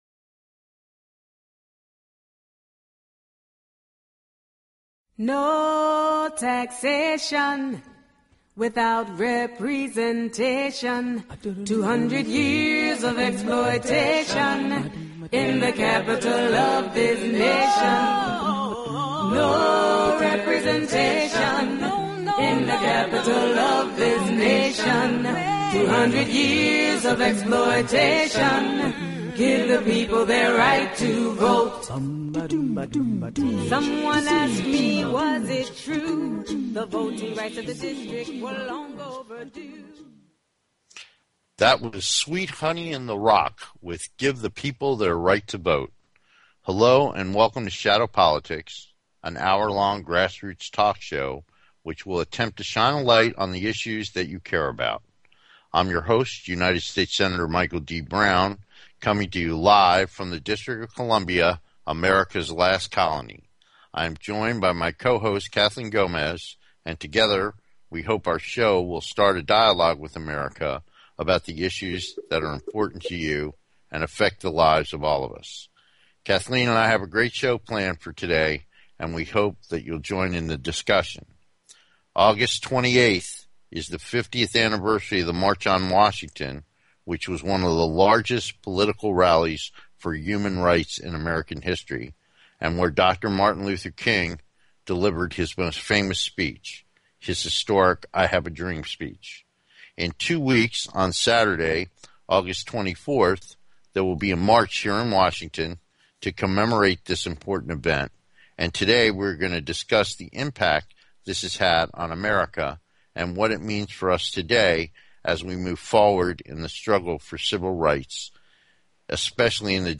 The show is not only informative but intertaining, and they both convey their opinions with humor, wit and a strong rapport.
Shadow Politics is a grass roots talk show giving a voice to the voiceless.
We look forward to having you be part of the discussion so call in and join the conversation.